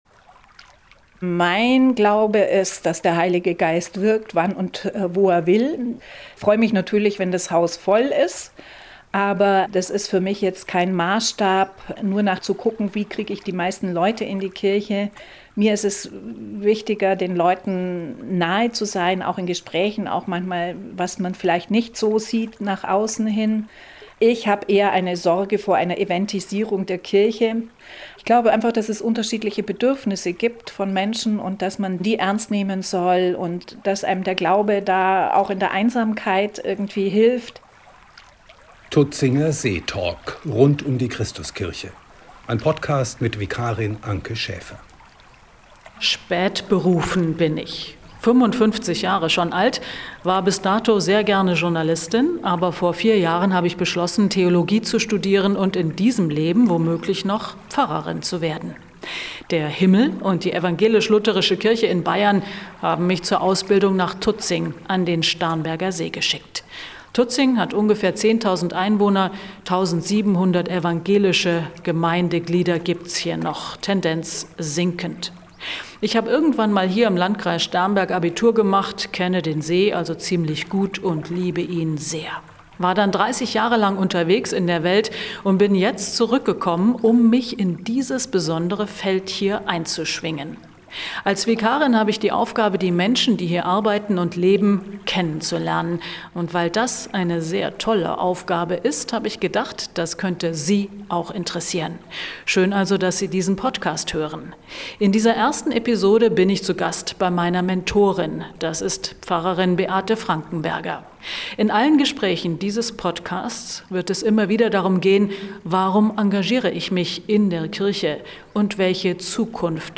Kirche – zwischen Events und Seelsorge – Ein Gespräch